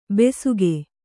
♪ besuge